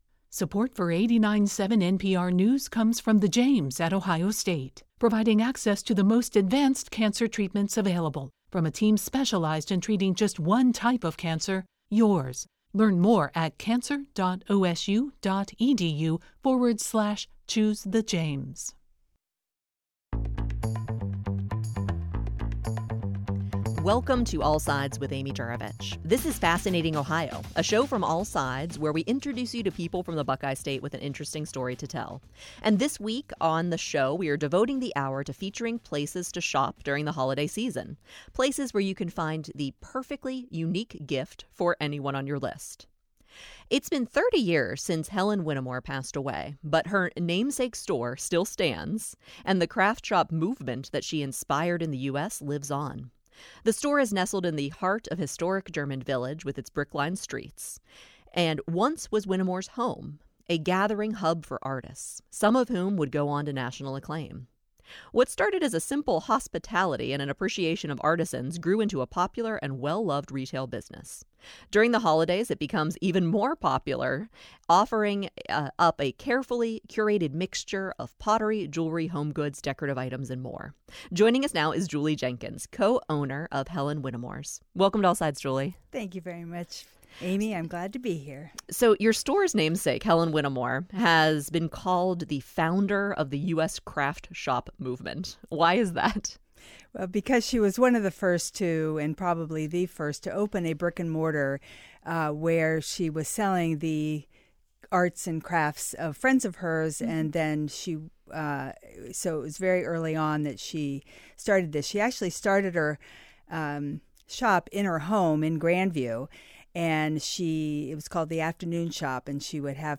daily news program